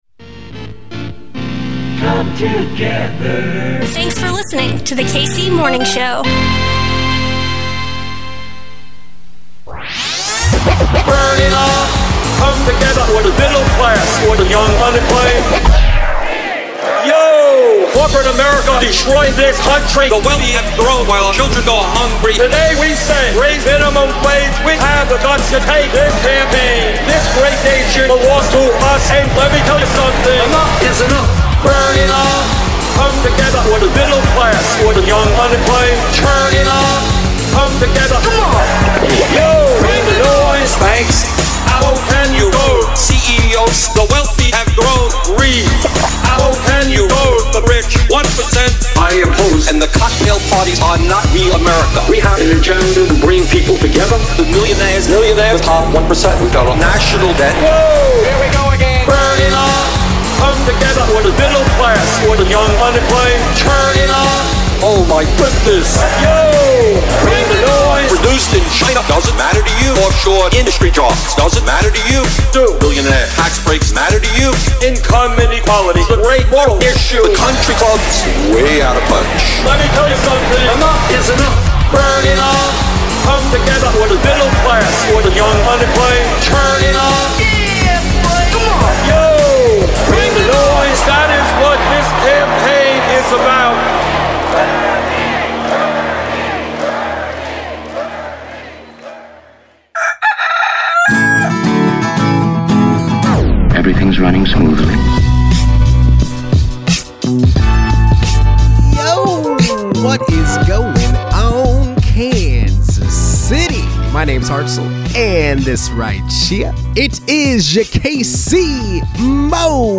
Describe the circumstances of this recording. LIVE from Progressive Central 2024 at the Chicago Teachers Union in Chicago, Illinois!